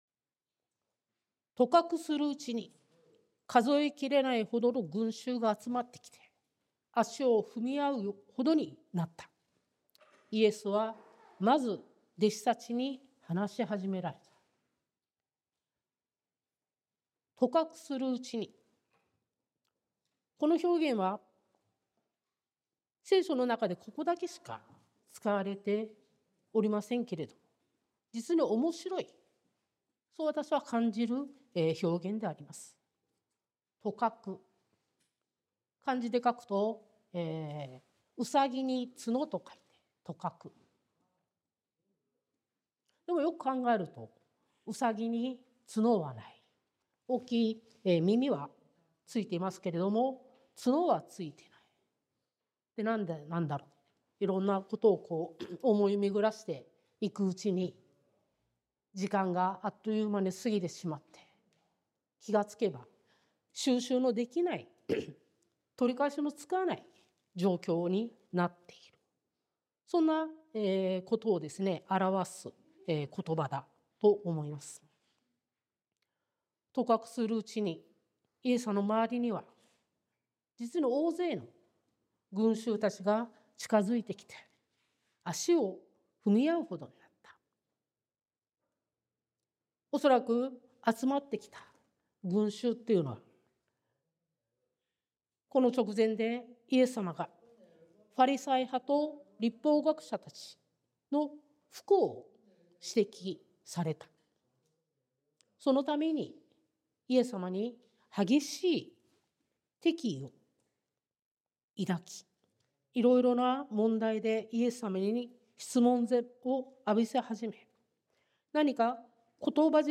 sermon-2024-11-10